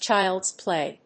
アクセントchíld's plày